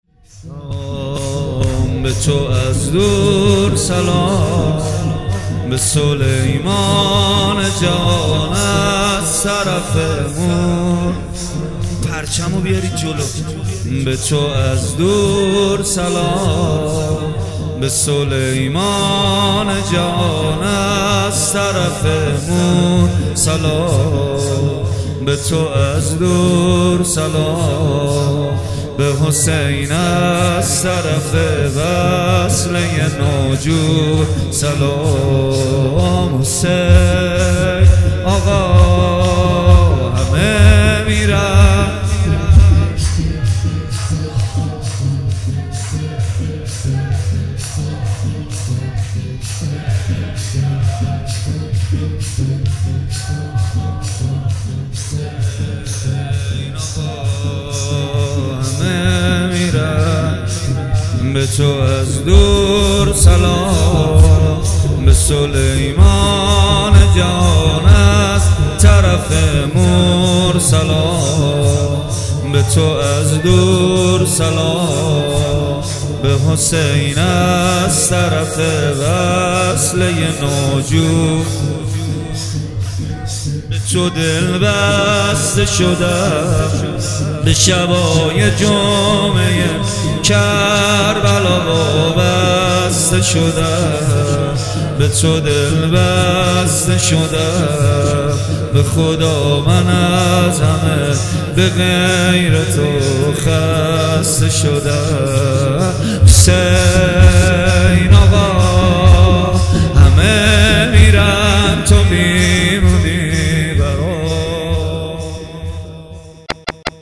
آخرین خبر | مداحی شب اول محرم 1399 با نوای کربلایی محمد حسین پویان‌فر